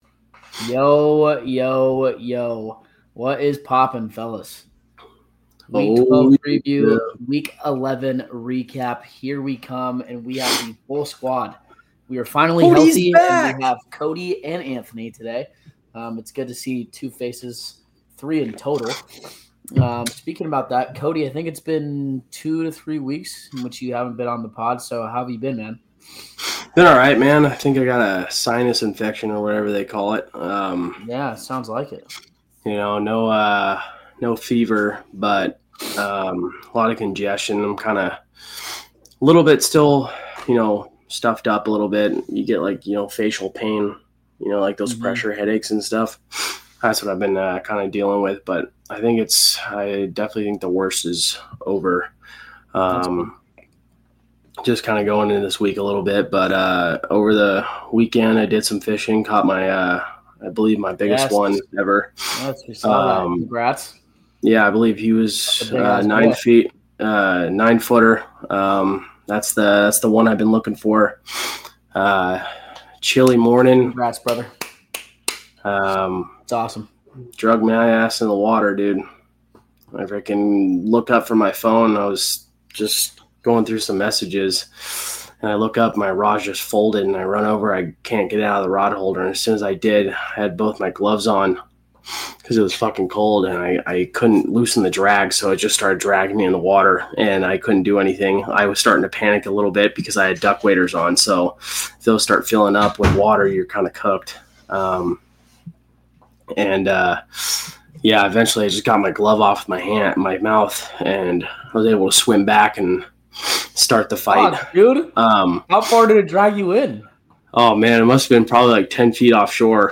In this week's episode, the full squad is back and healthy! Join in as they dive into a thorough recap of Week 11 in the NFL, with plenty of laughs, heated moments, and insightful analysis. They discuss the Patriots' ongoing success, the Bills' performance against the Buccaneers, dramatic games like Seahawks vs. Rams, the Chiefs' struggles, and the dominance of the Eagles and Broncos.